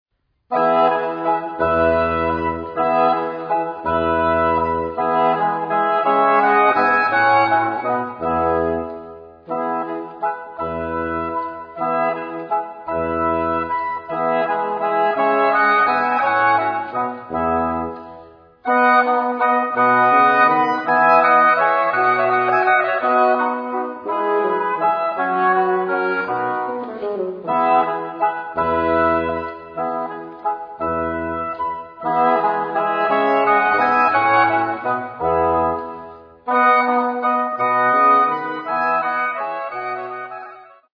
playing oboes, oboe da caccia, tenor oboe and bassoon.
A great CD to fill the house (or the iPod earphones) with the mellifluous sound of baroque oboes.